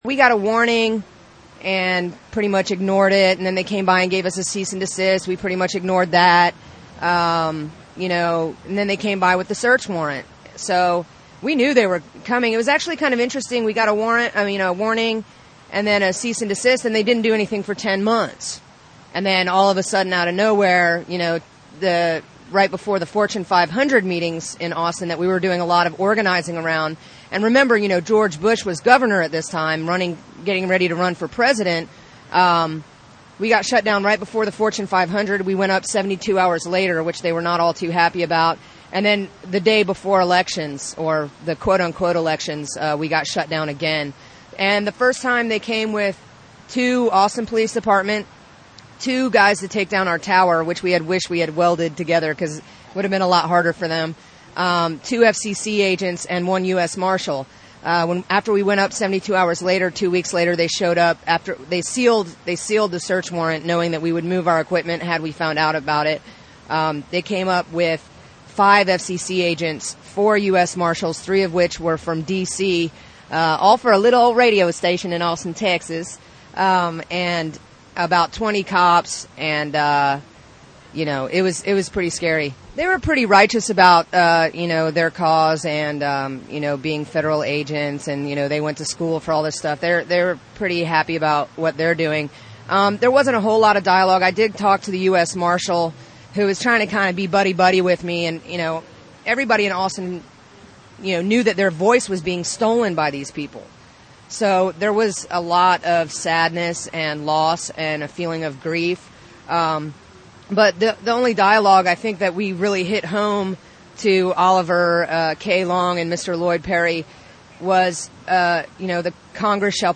We'll begin with cuts from interviews done with a handful of Mosquito Fleet participants; it was hard to catch people flitting back and forth between transmitters, and some people don't want any publicity.